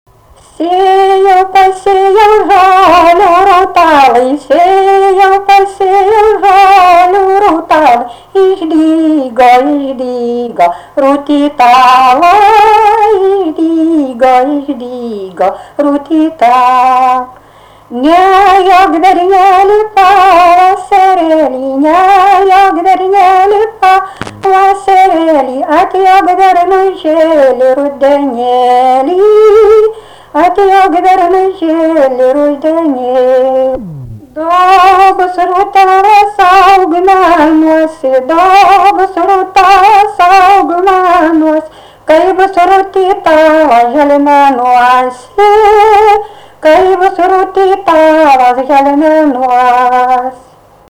Dalykas, tema daina
Erdvinė aprėptis Suvainiai
Atlikimo pubūdis vokalinis